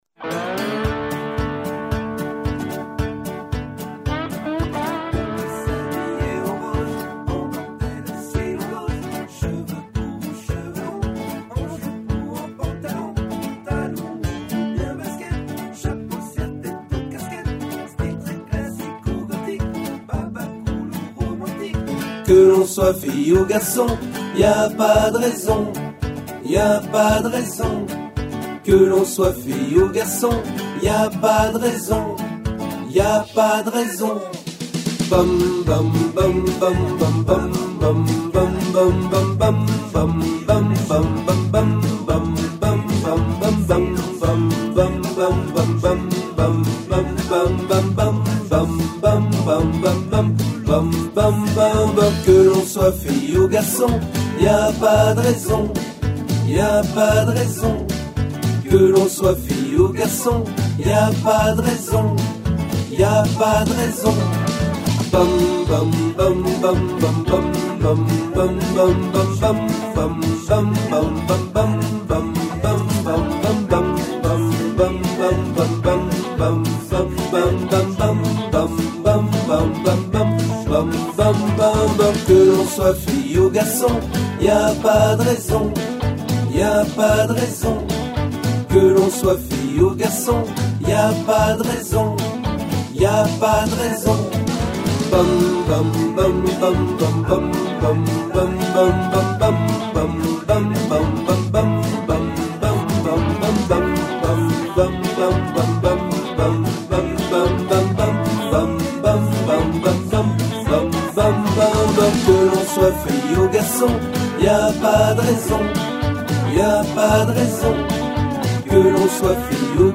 Ténor